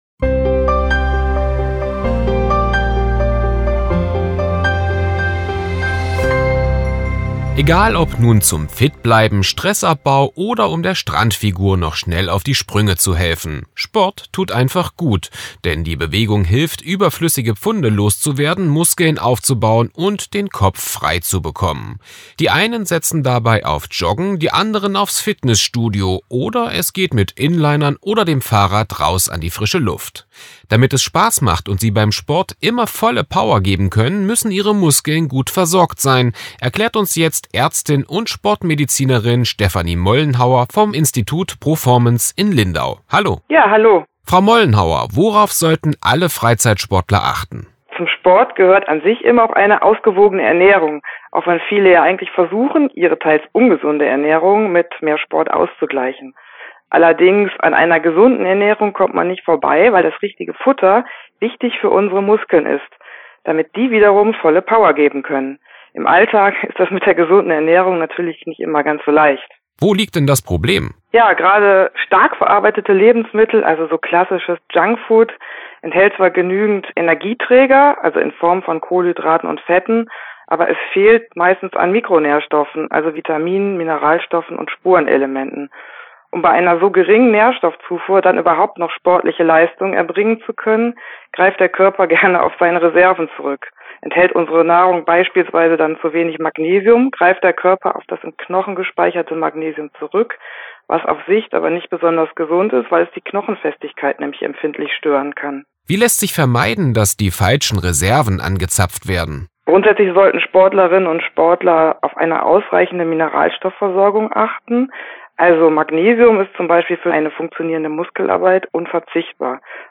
Radiobeitrag "Sport und Magnesium"
diasporal-podcast-sport-magnesium.mp3